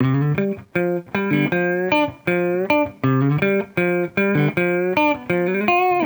Index of /musicradar/sampled-funk-soul-samples/79bpm/Guitar
SSF_TeleGuitarProc1_79A.wav